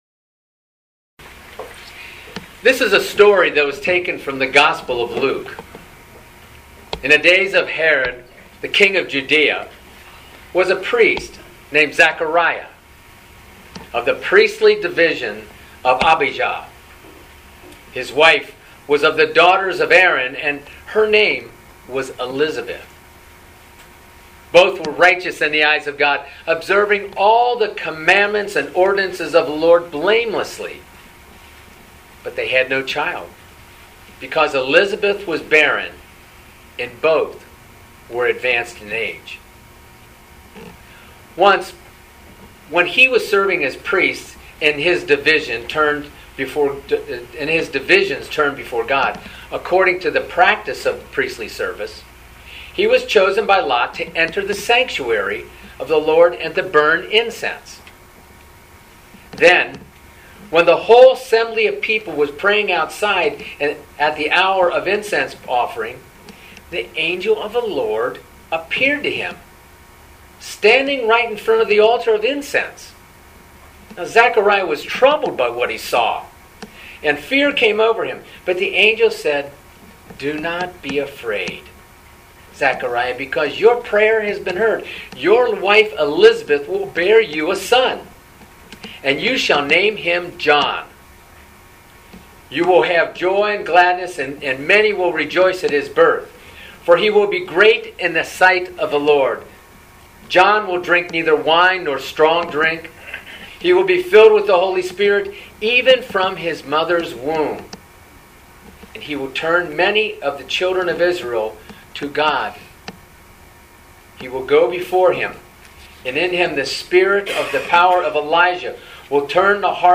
Living Beatitudes Community Homilies: John the Baptist